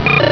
cries
cyndaquil.aif